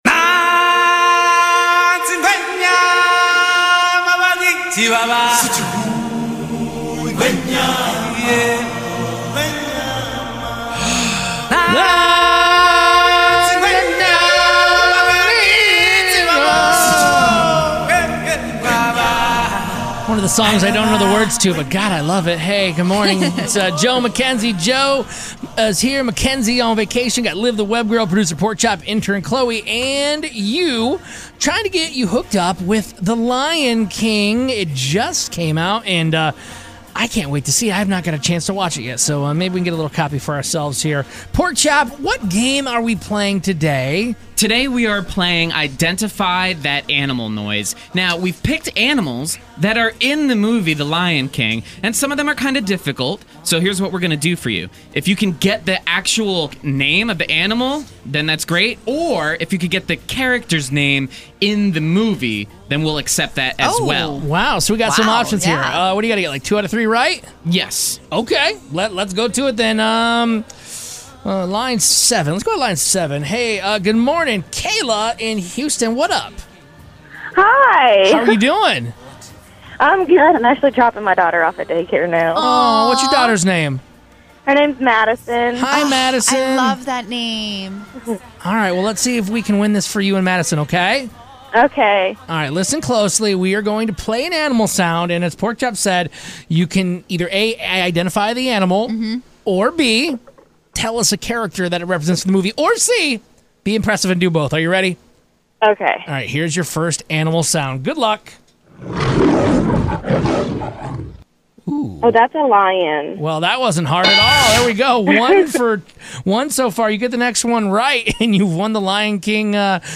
GAME: Guess the Animal Sounds